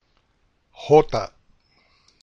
Letrajhota